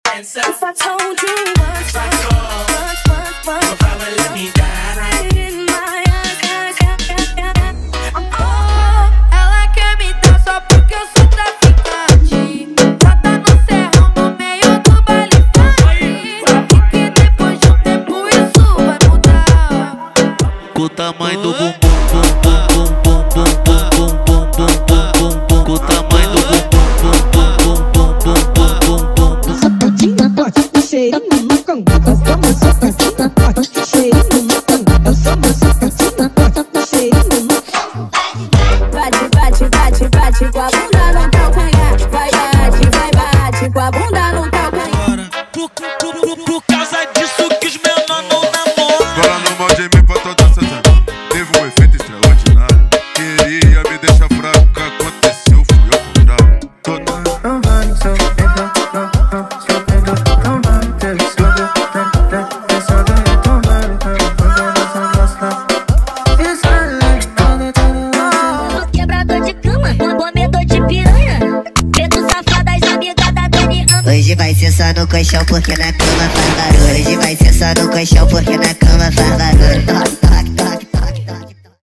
Os Melhores Brega Funk do momento estão aqui!!!
• Brega Funk = 50 Músicas
• Sem Vinhetas